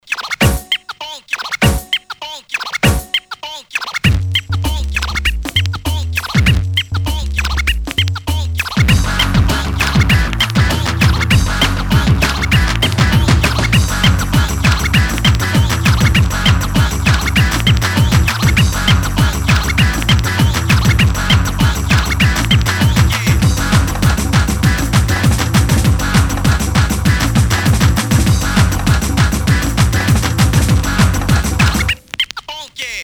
• Качество: 320, Stereo
dance
Electronic
без слов
club
быстрые
бодрые
breakbeat
happy hardcore